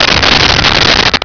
Sfx Pod Seb Flame
sfx_pod_seb_flame.wav